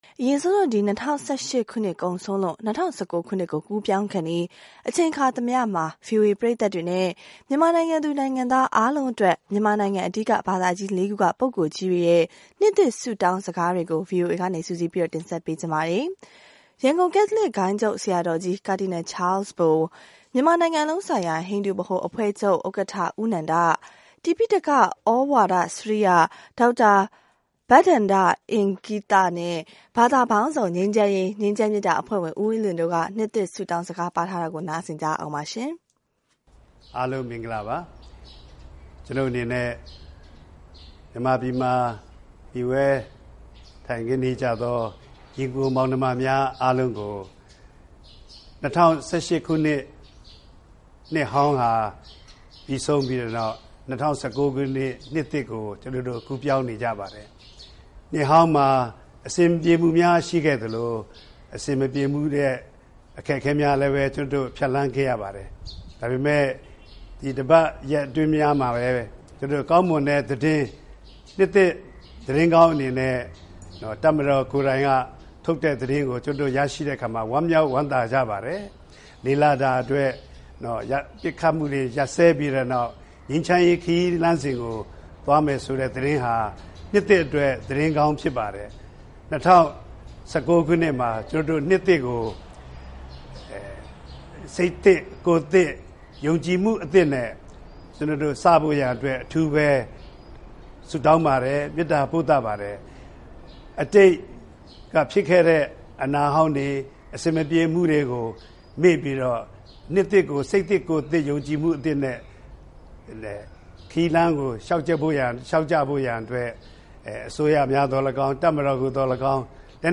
၂၀၁၈ ခုနှစ်ကုန်ဆုံးလို့ ၂၀၁၉ ခုနှစ်ကို ကူးပြောင်းခါနီး အခါသမယမှာ ဗွီအိုအေပရိသတ်များနဲ့ မြန်မာနိုင်ငံသူ နိုင်ငံသားအားလုံးအတွက် မြန်မာနိုင်ငံ အဓိက ဘာသာကြီးလေးခုမှ ပုဂ္ဂိုလ်ကြီးများရဲ့ နှစ်သစ်ဆုတောင်းစကားများကို ဗွီအိုအေက စုစည်း တင်ဆက်ပေးလိုက်ပါတယ်။